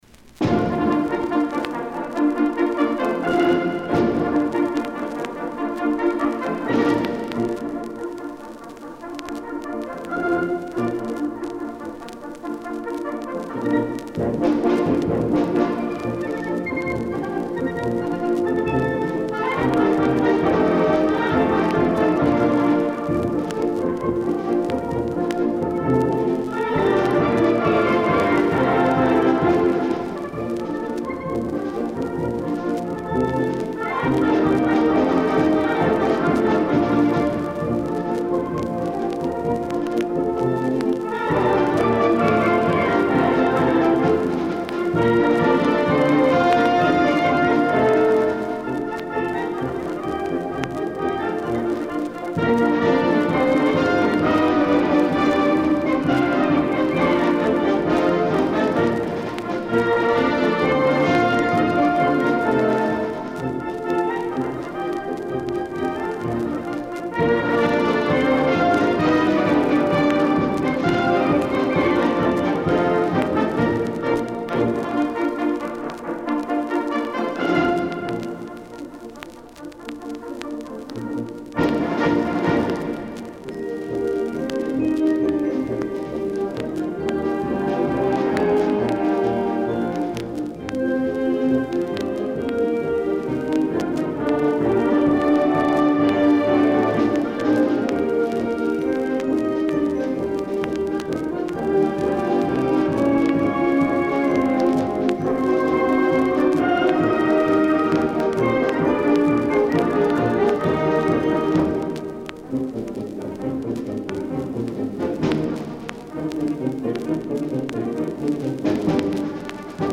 Performance by East Carolina College Concert Band - ECU Digital Collections